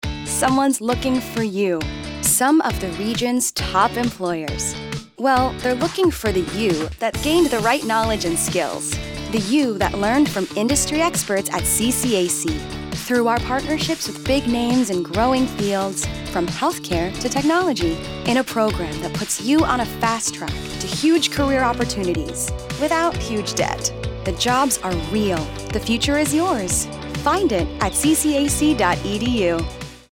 Community College of Allegheny County | Radio Advertising – Single Spot